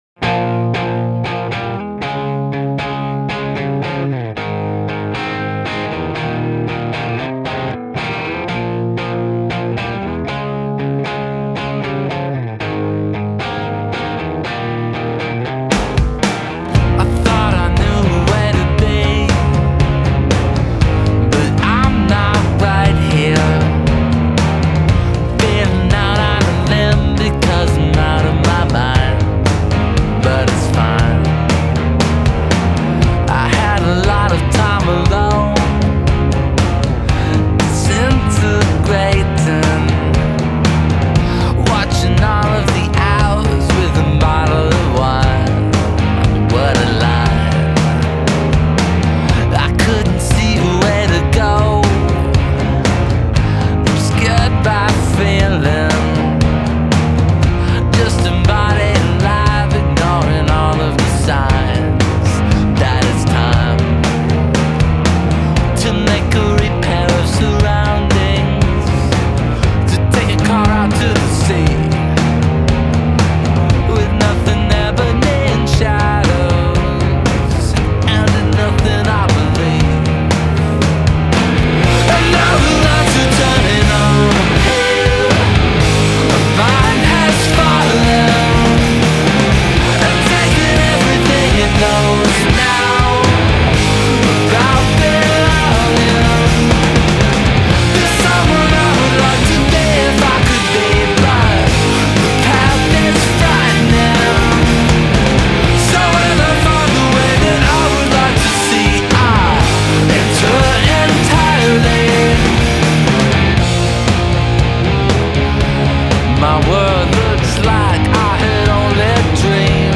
grunge revivalists